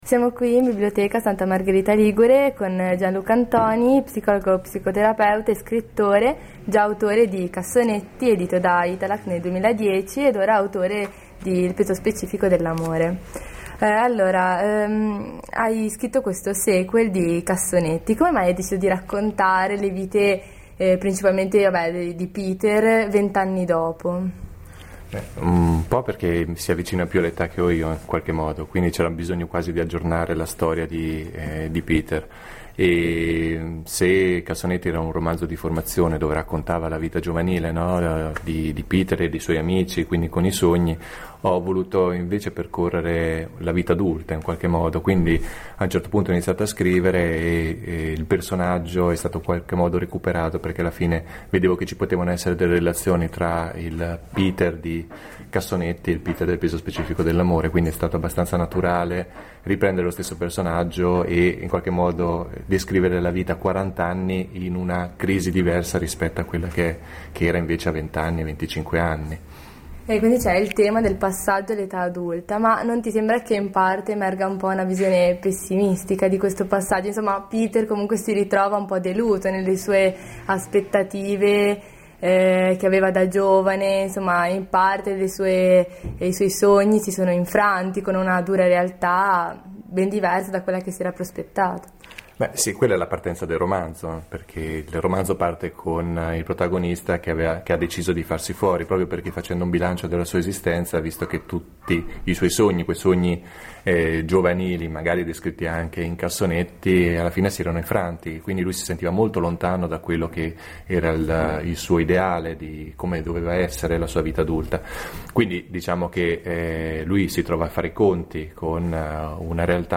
Intervista
presso la biblioteca civica di Santa Margherita Ligure